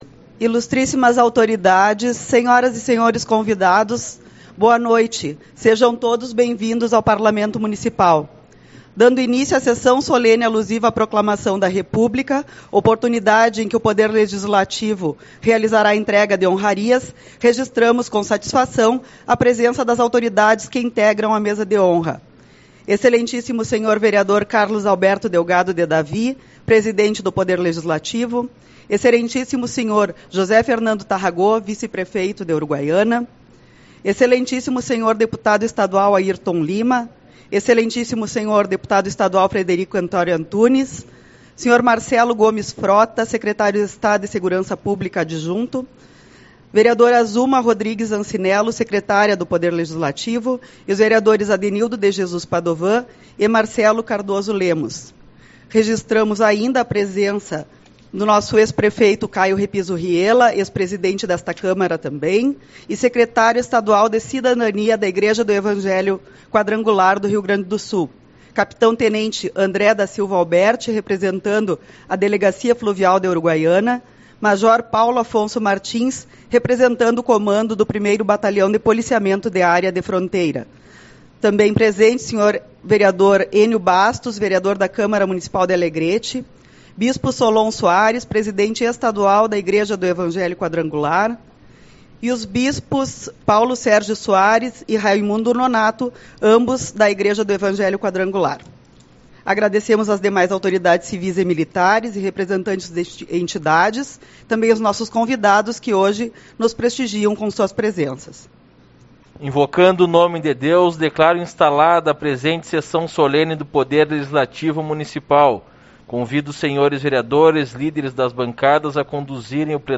11/11 - Sessão Solene-Proclamação República